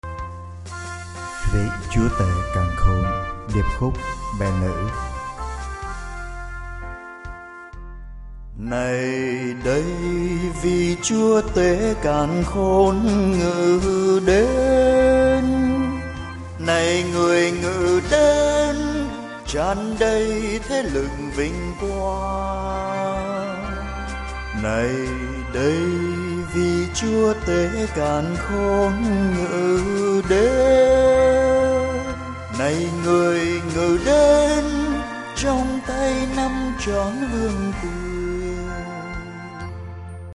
ViChuaTeCanKhon_DK_Sop.mp3